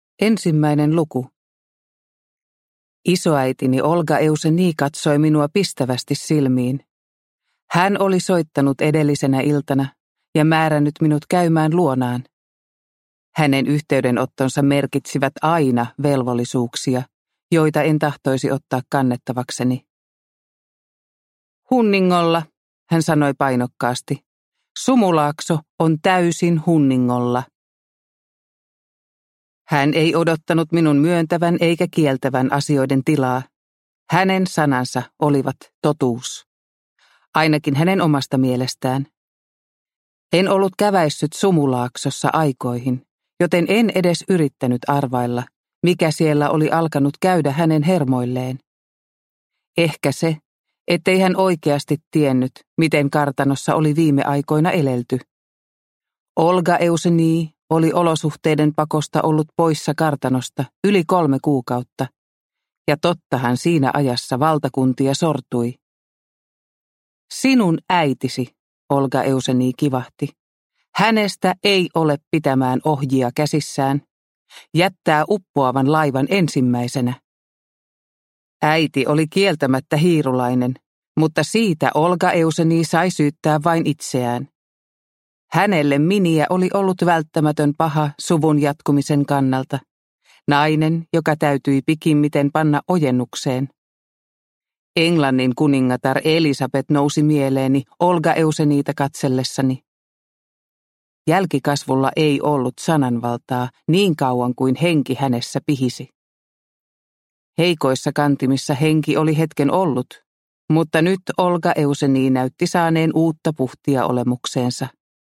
Takaisin Sumulaaksoon – Ljudbok – Laddas ner